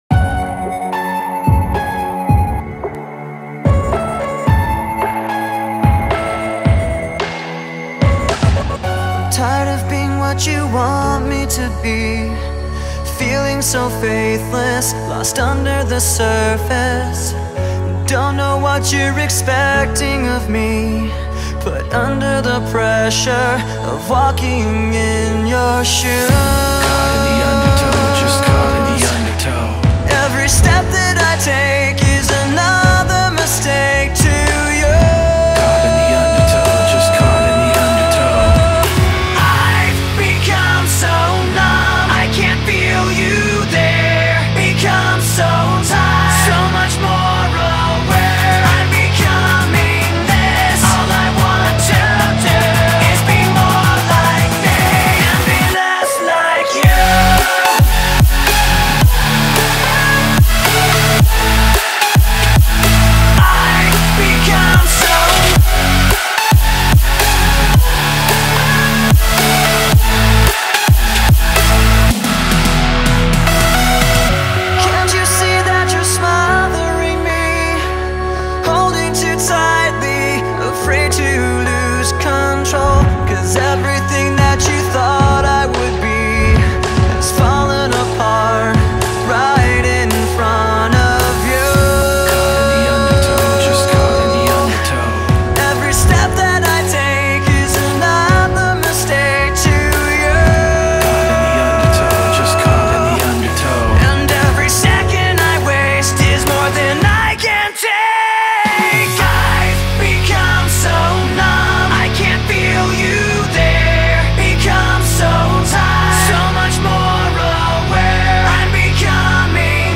2024-10-23 19:21:06 Gênero: Rock Views